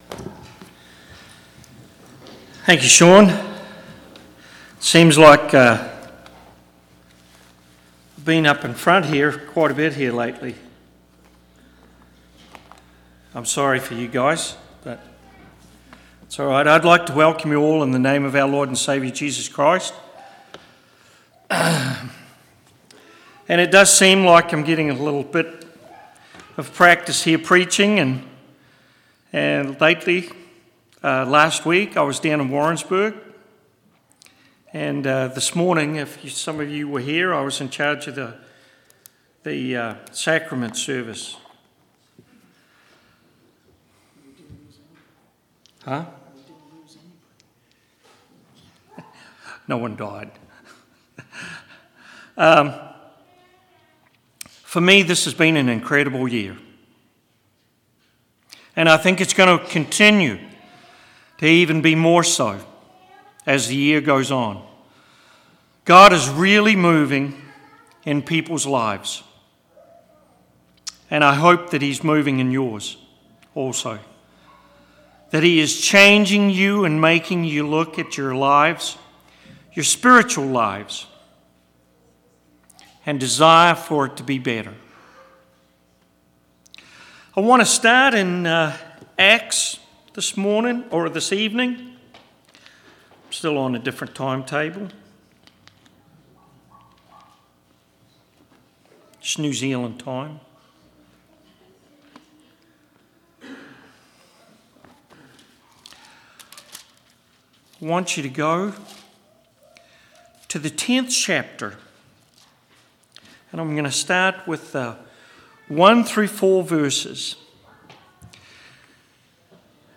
5/3/2015 Location: Temple Lot Local Event